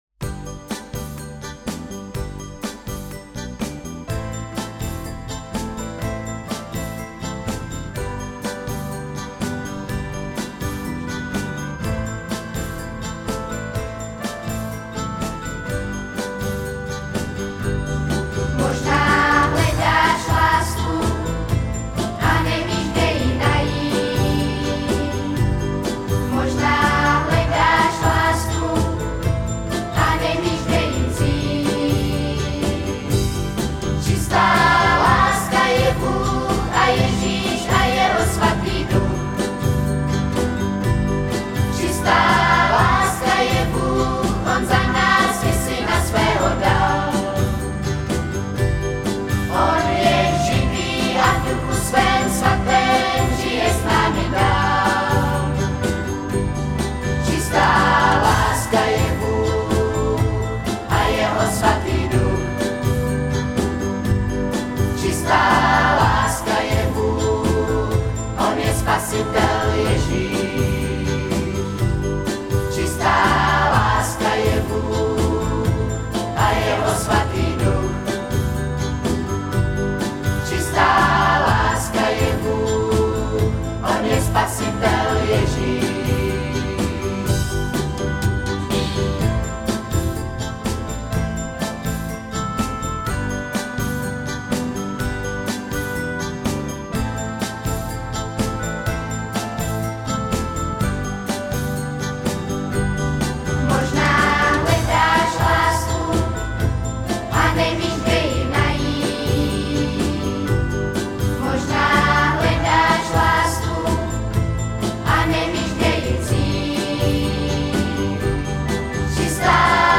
Křesťanské písně